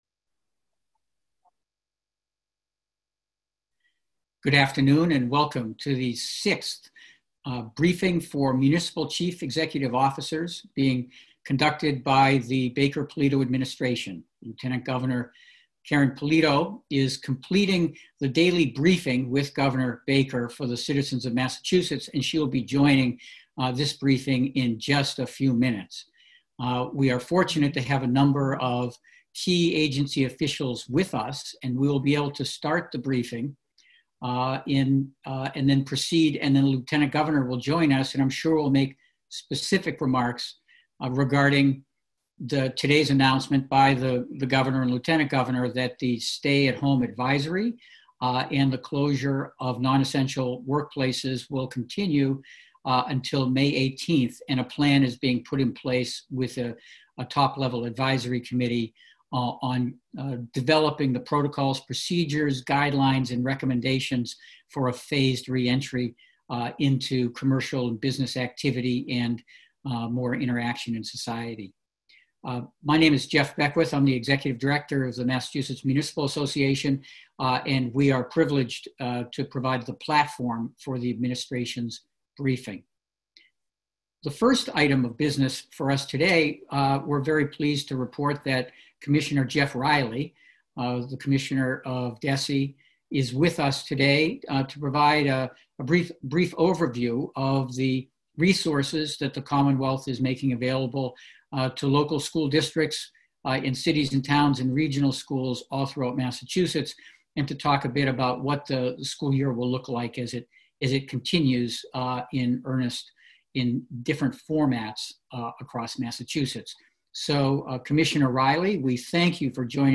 MMA_Admin_COVID19_BriefingAudio_April28.mp3